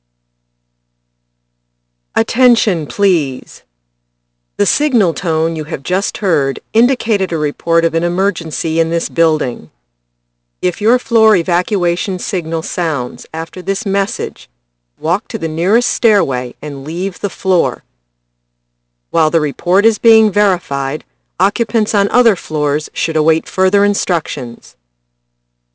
高层报警激活
1. 激活：发出警报（预信号）音。音调将以大约1秒的间隔产生3轮4个音调。
2. 听得见的消息（重复2次）“请注意，您刚才听到的信号音表示本大楼有紧急情况报告。如果您所在楼层的疏散信号在此消息后响起，请走到最近的楼梯出口并离开楼层。在这份报告得到核实的同时，另一层楼的住户请等待进一步指示。”
Fire-Alarm-Audible-Message.wav